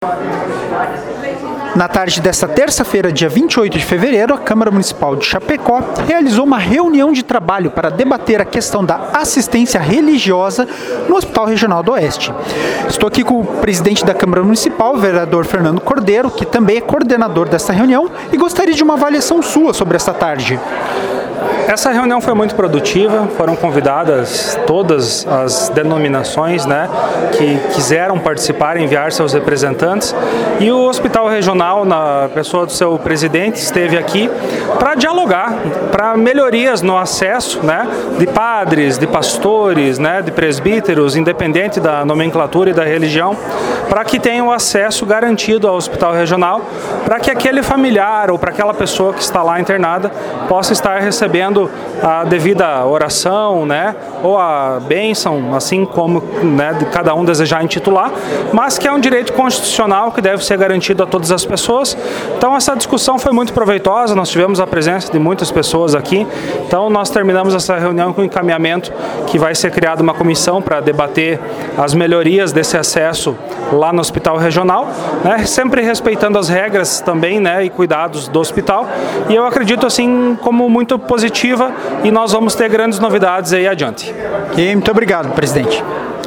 Áudio do vereador Fernando Cordeiro sobre a Reunião de Trabalho